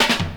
1_Step_Roll_160.wav